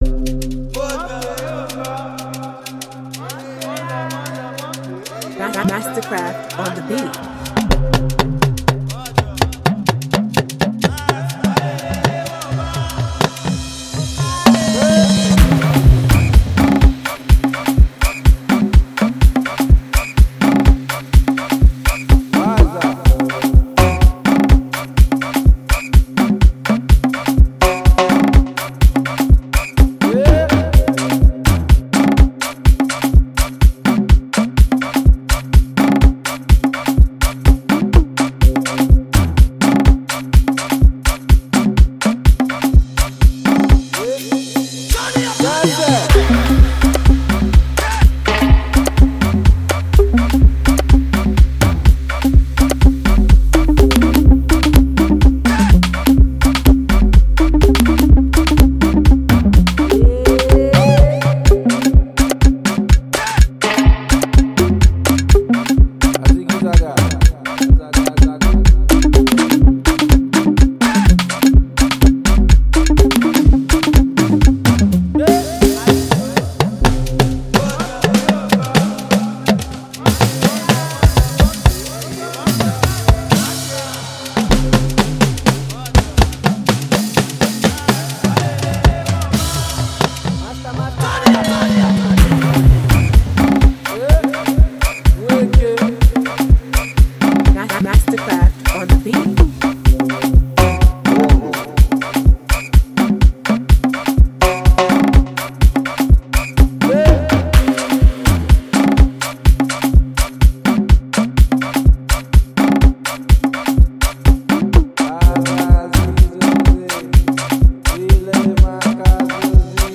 Afro-popAudioInstrumental